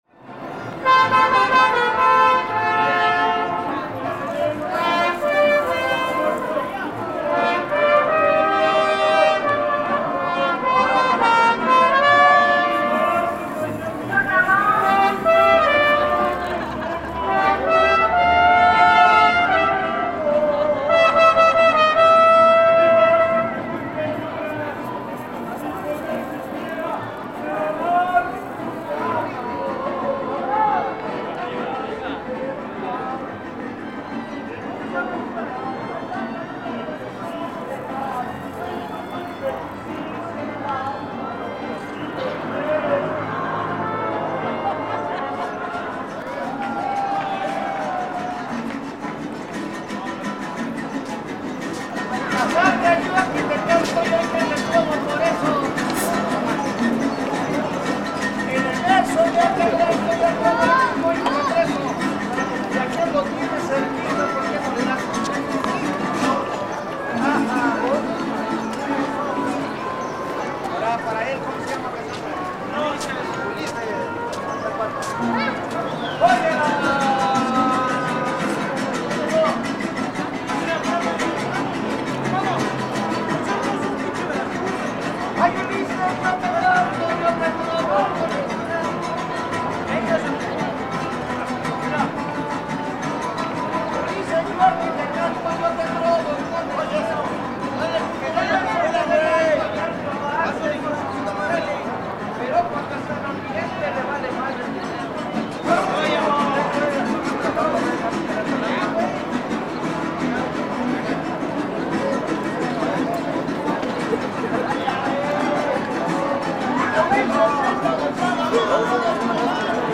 Distant Mariachis bands play at Plaza Garibaldi in Mexico City. Mono 48kHz 24bit.